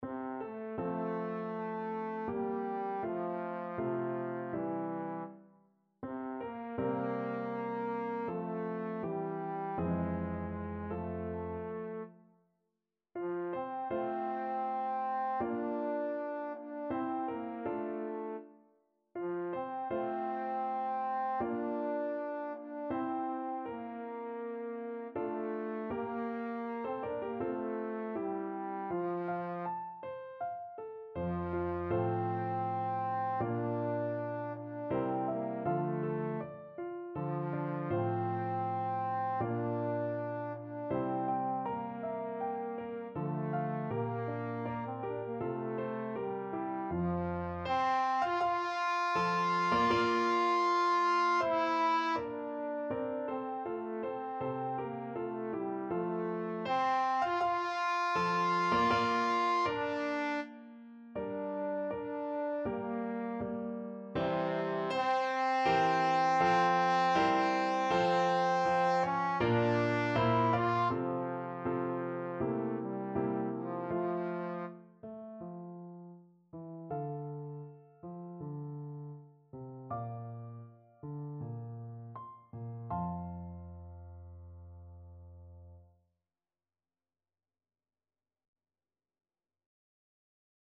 Trombone version
Trombone
F major (Sounding Pitch) (View more F major Music for Trombone )
Andante
4/4 (View more 4/4 Music)
Classical (View more Classical Trombone Music)